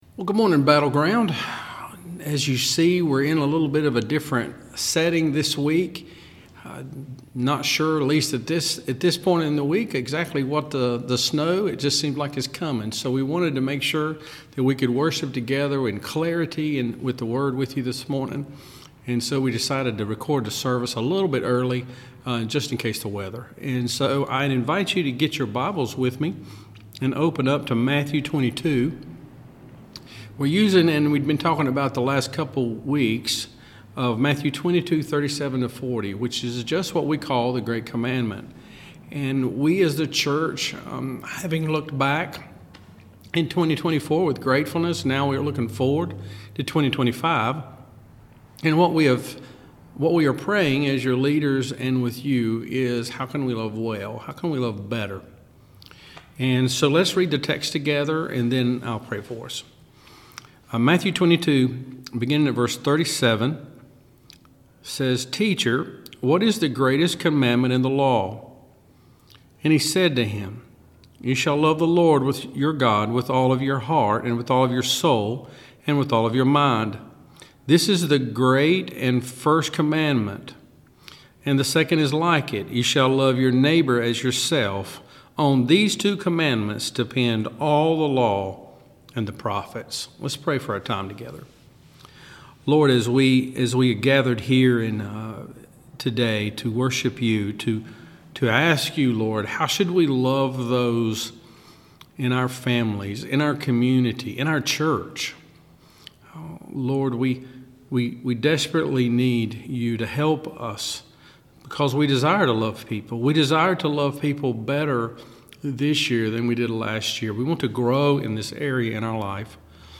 Battleground Community Church Sermons
Sermon Series